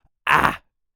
Male_Medium_Vowel_02.wav